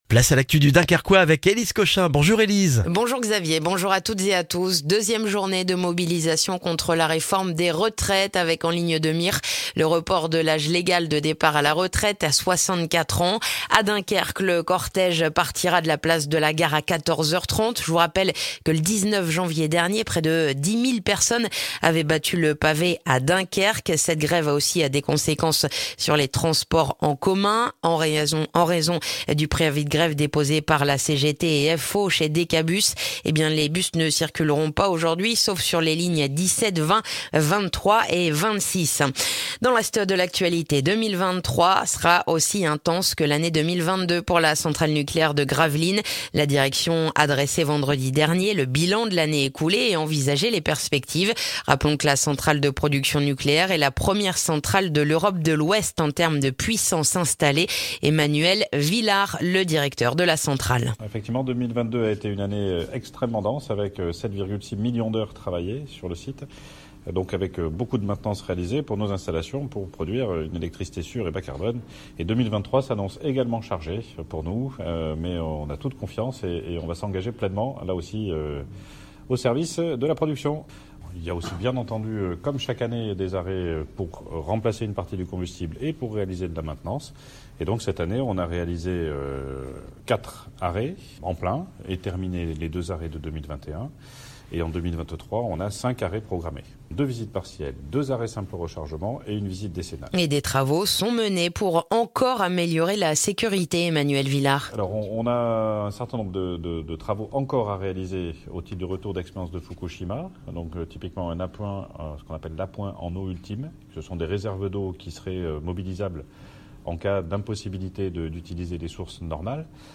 Le journal du mardi 31 janvier dans le dunkerquois